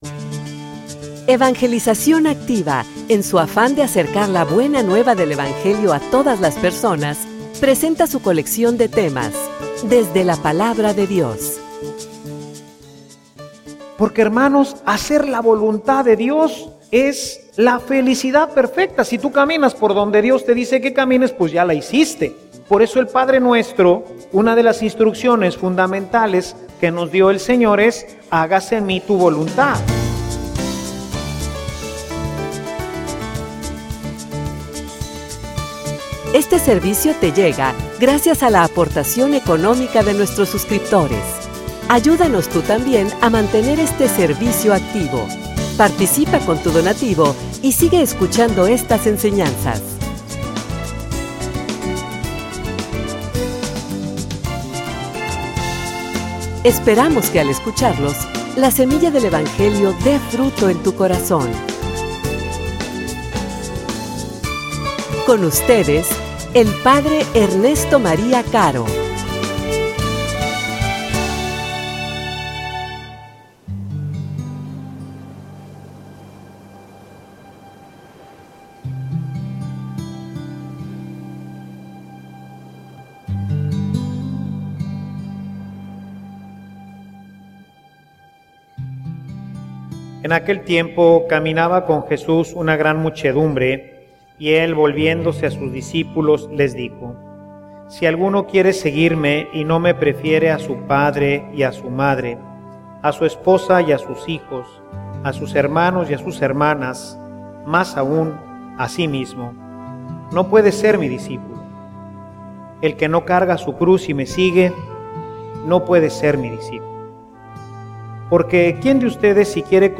homilia_Tu_como_tomas_tus_decisiones.mp3